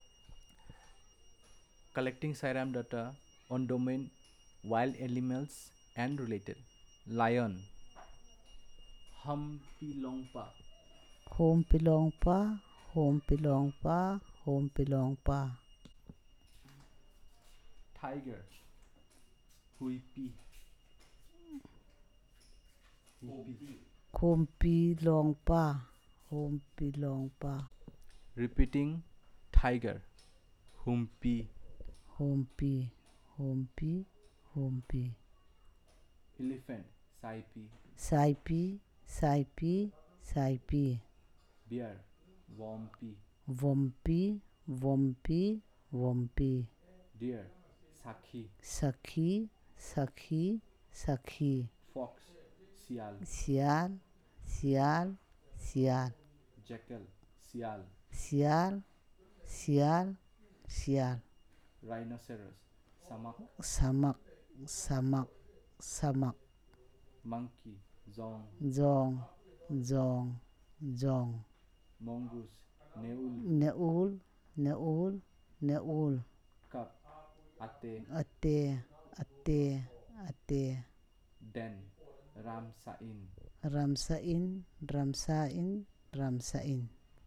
Elicitation of words about wild animals and related